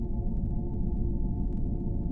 NoPower.wav